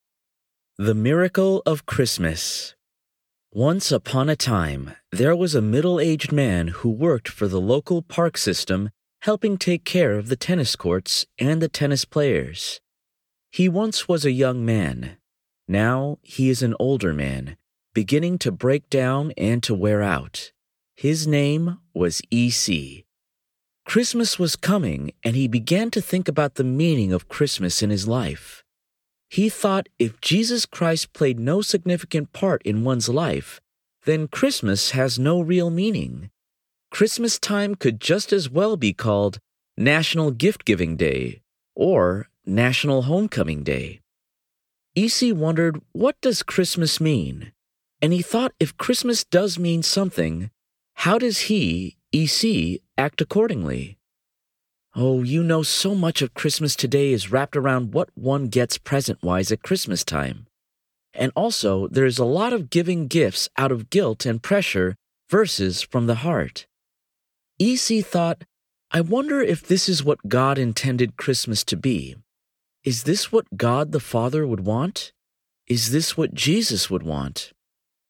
Audio sample of the book.